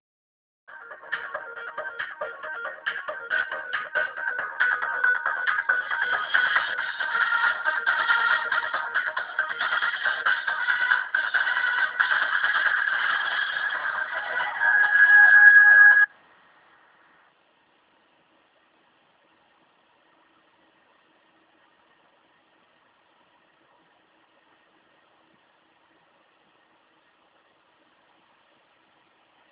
Там хаус транс